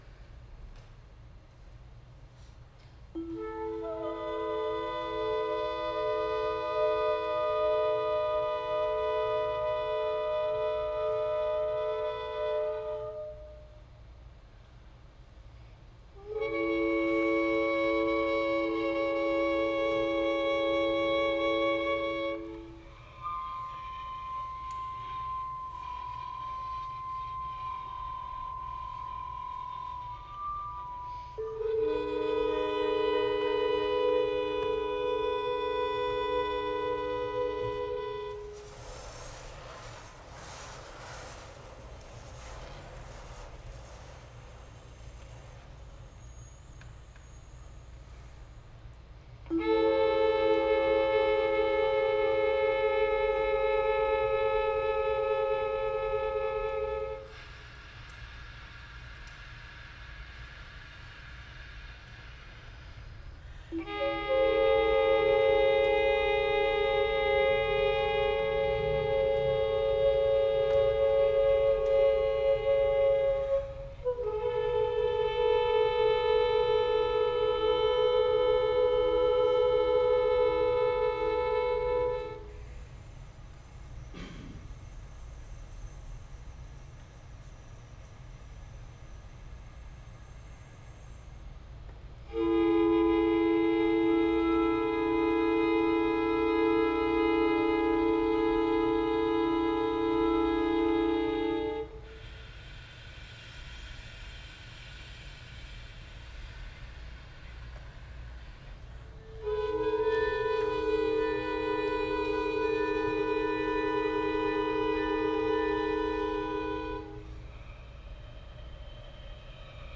impossibly small sounds and glacially slow music
The performance will feature the world premiere of irreversible histories of disturbance, a long-form composition employing handmade instruments and novel scoring techniques that emphasize communal interdependence, performer agency, and playful curiosity about sound.
flute
clarinet
voice
violin
percussion